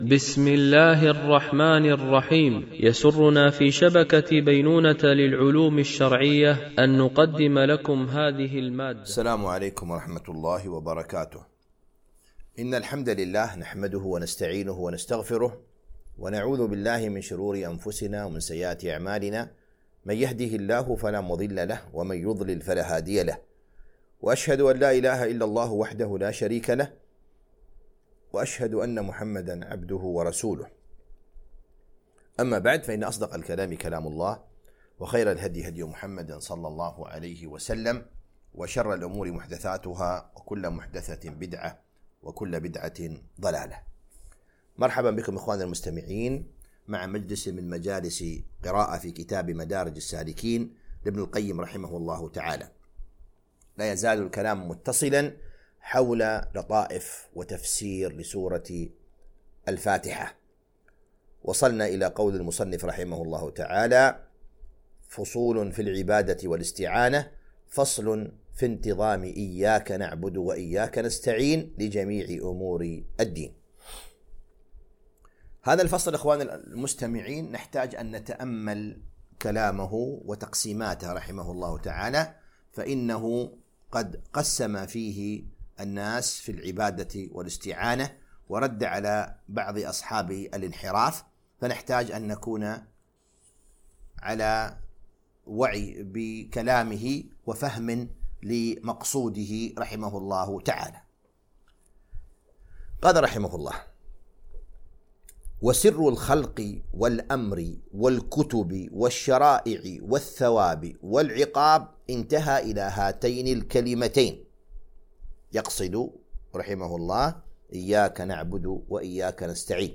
قراءة من كتاب مدارج السالكين - الدرس 10